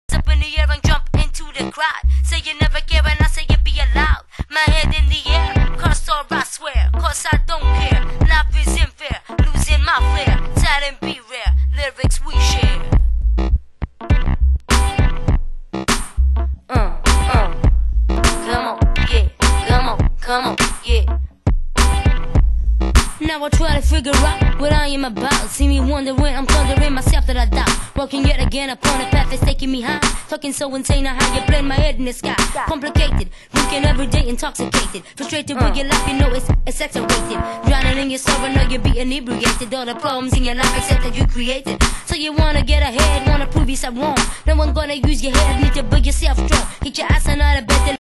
ファニーなラップがエレクトロ調ブレイクに乗って展開する個性的なトラック！